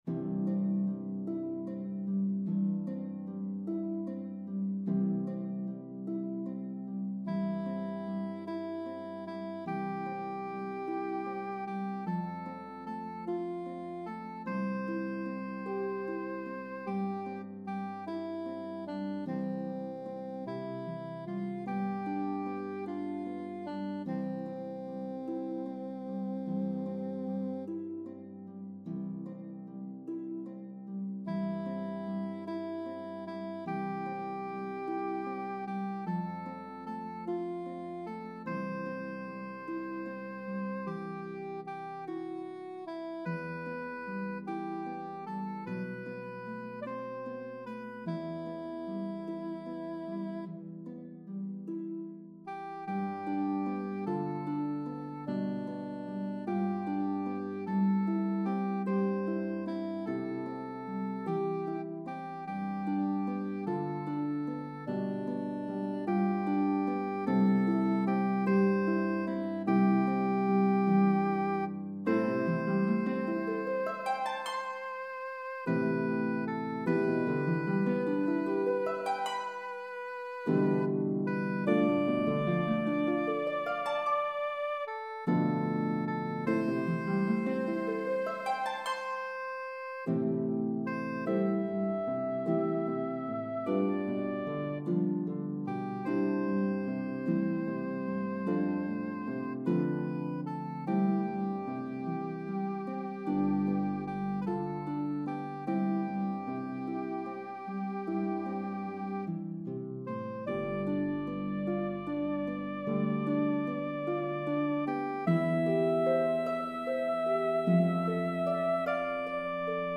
34+ string Lever Harp
The melody of the two verses varies in rhythm & pitches.